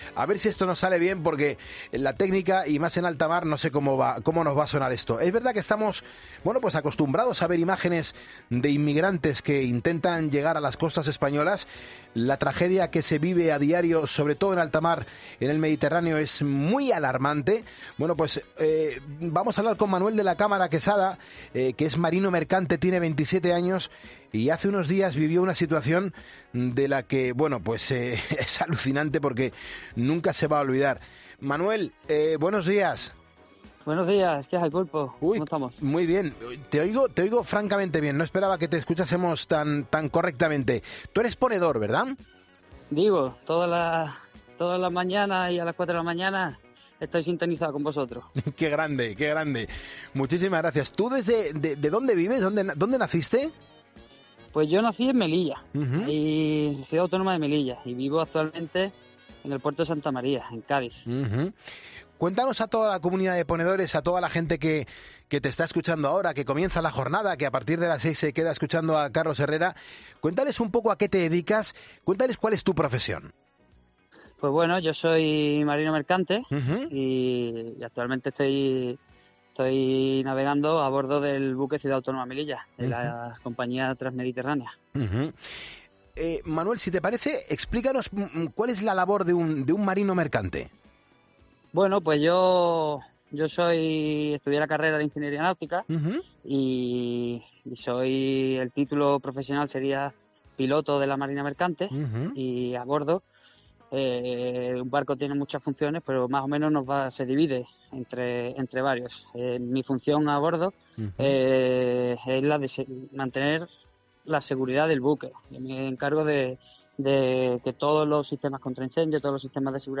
Hemos podido hablar con él desde su barco y lo primero que nos reconoce es que es ponedor.